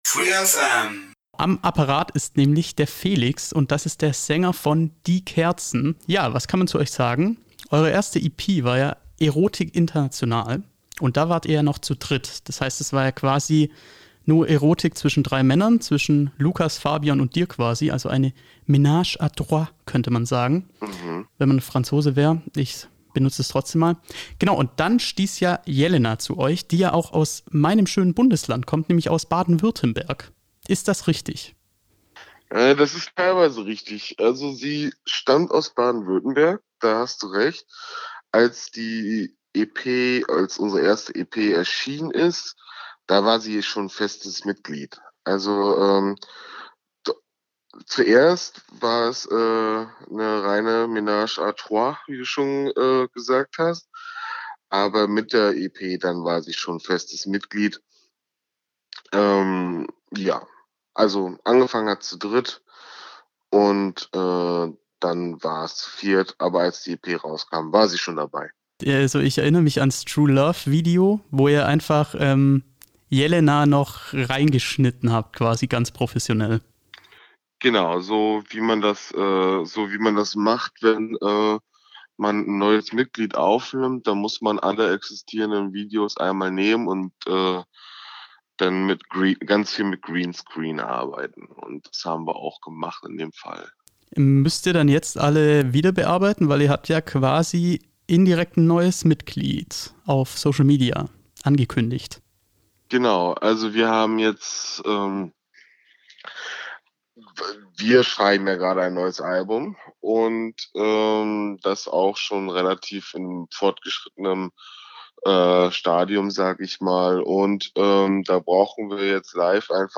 Wir haben uns Die Kerzen zum Telefoninterview eingeladen